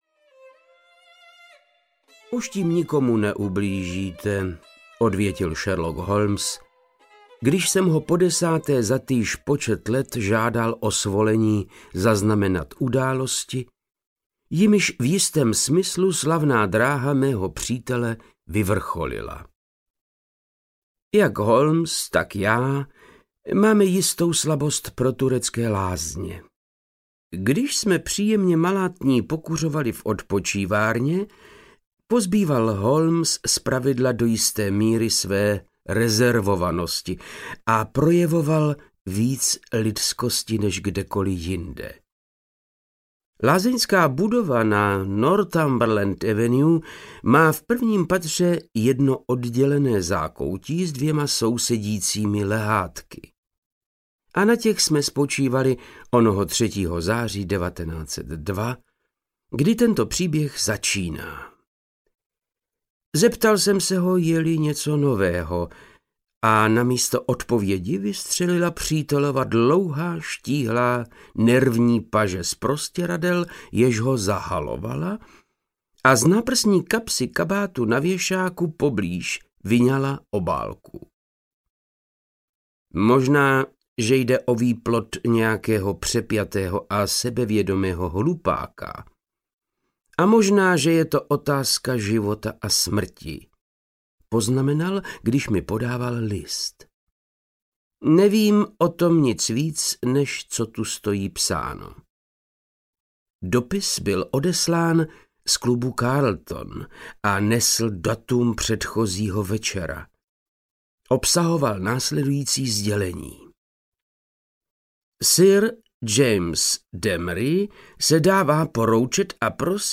Vznešený klient audiokniha
Ukázka z knihy
• InterpretVáclav Knop